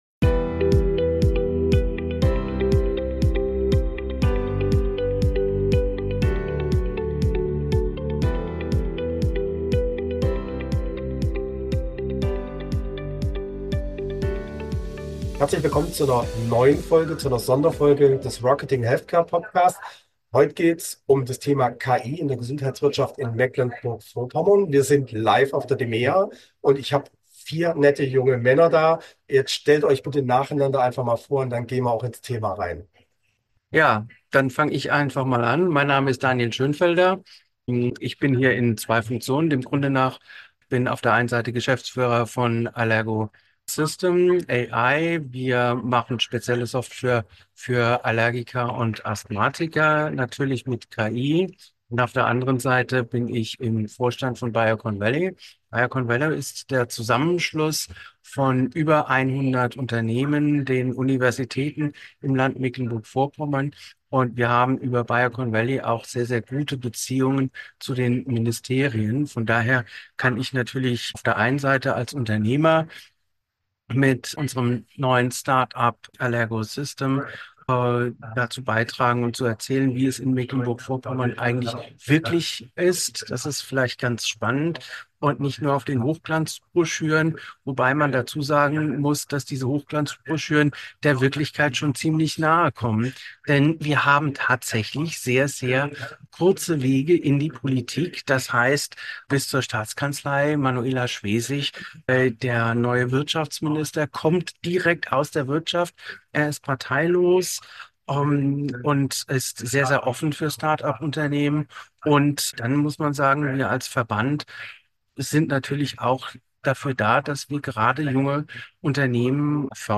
Live von der DMEA 2025